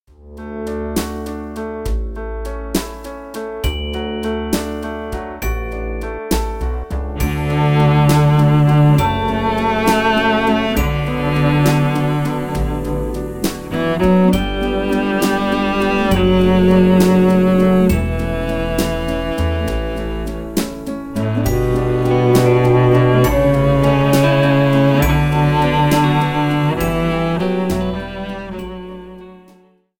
Recueil pour Violoncelle